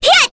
Kirze-Vox_Attack5_kr.wav